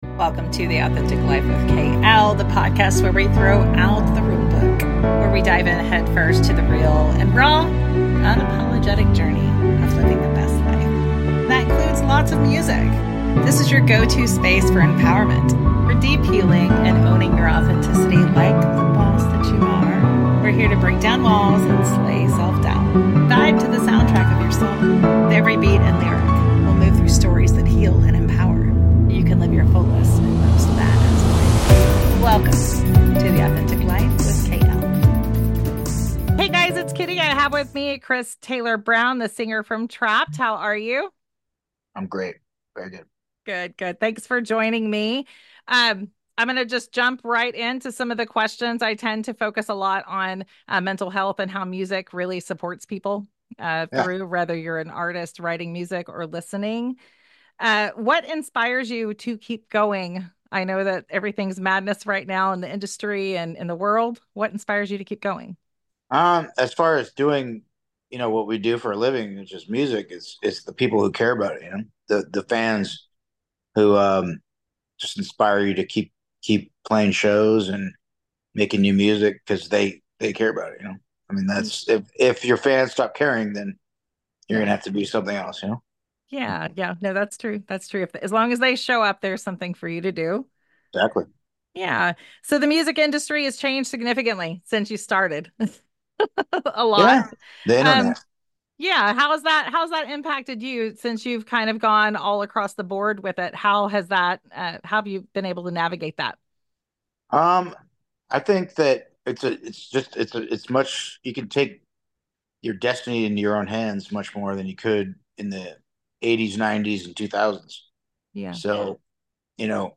This is an honest and powerful conversation about healing, growing, using your platform for purpose, and finding clarity in the chaos.